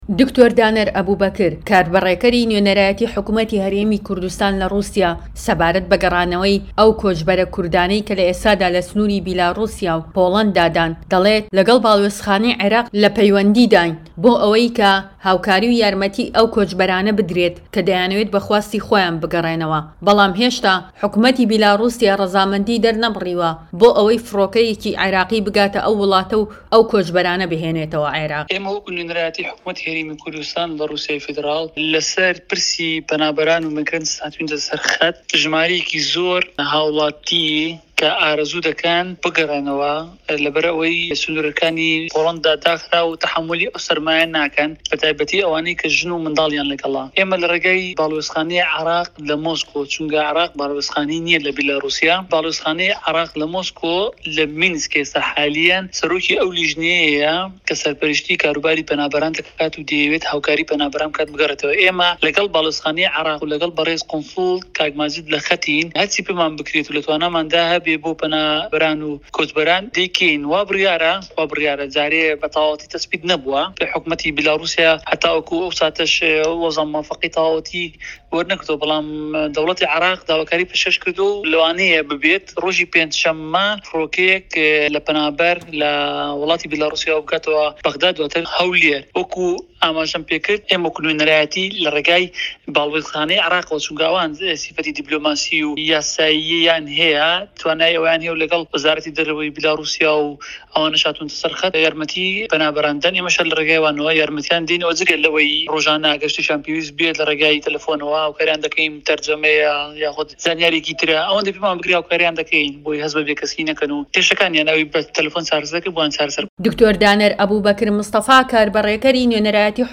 هه‌رێمه‌ کوردیـیه‌کان - گفتوگۆکان
دەقی وتەکانی د.دانەر لەم ڕاپۆرتەدا ببیستە Nov 18-021